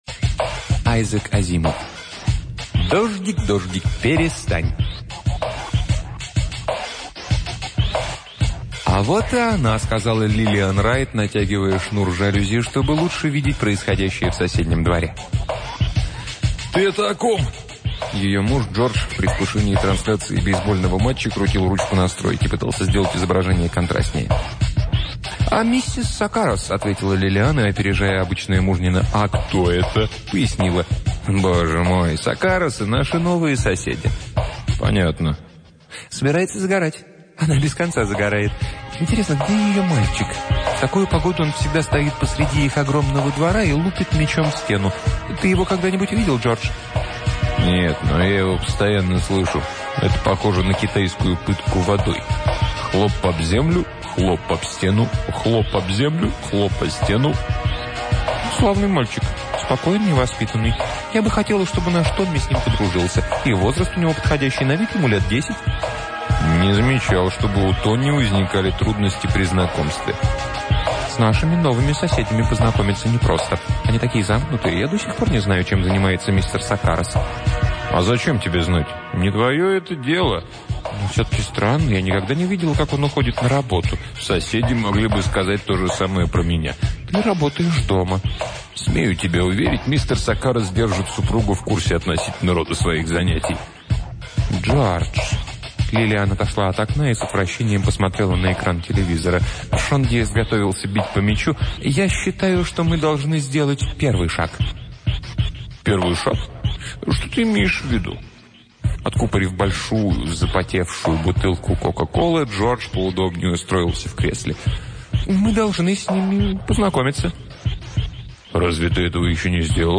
Аудиокнига Айзек Азимов — Дождик дождик перестань